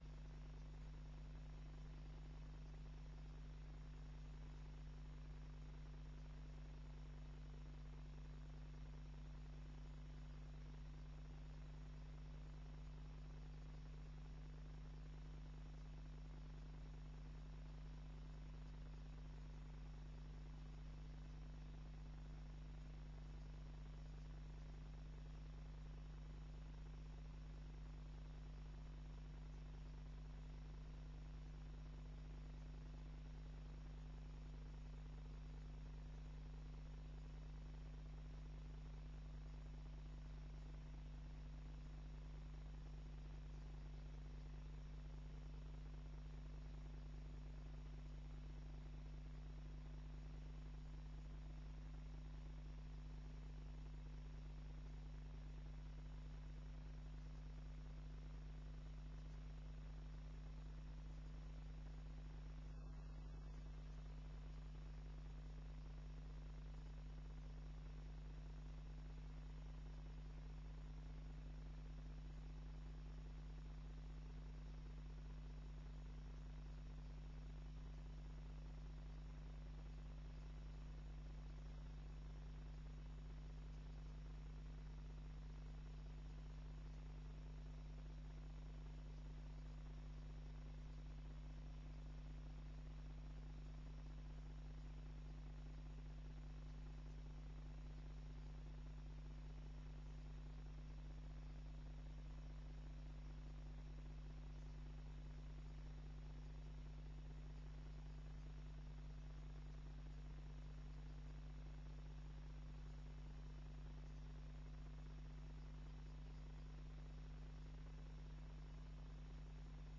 Persconferentie brand Yde/de Punt